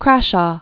(krăshô), Richard 1613?-1649.